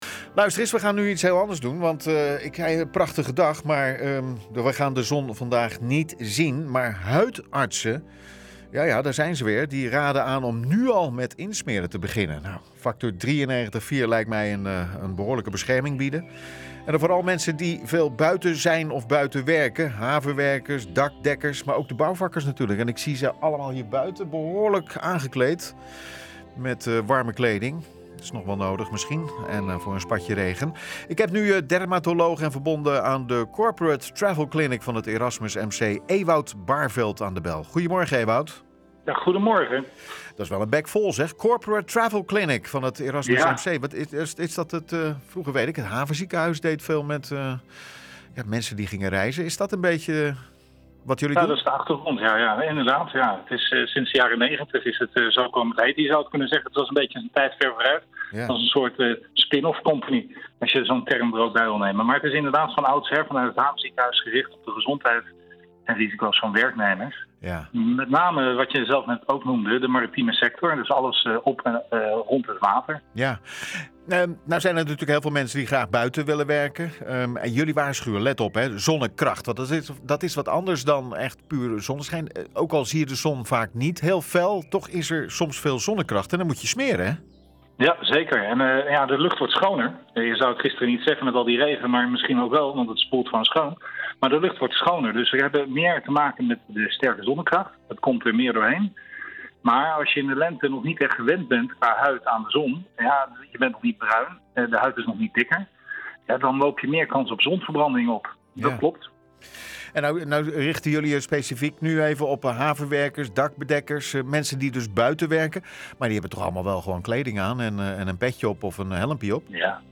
Beluister het interview op de radio hier.